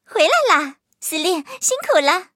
M2中坦战斗返回语音.OGG